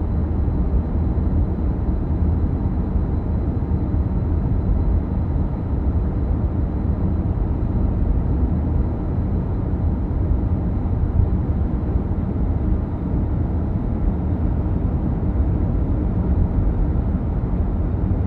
driving.ogg